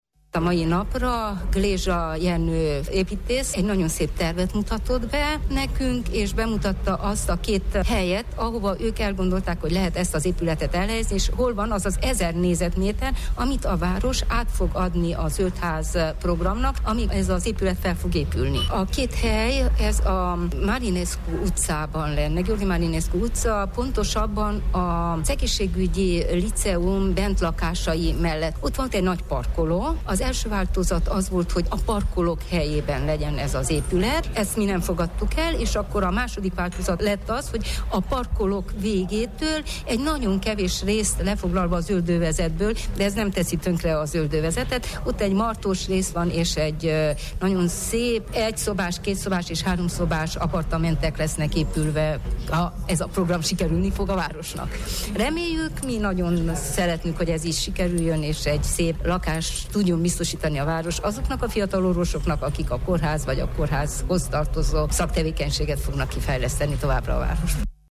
Egészségügyben dolgozók számára épül tömbház Marosvásárhelyen. Fúró Judit önkormányzati tanácsos, az urbanisztikai és városfejlesztési bizottság tagja rádiónknak elmondta, amennyiben az ezzel kapcsolatos tervet jóváhagyják, akár már jövő évtől elkezdődhetnek a munkálatok.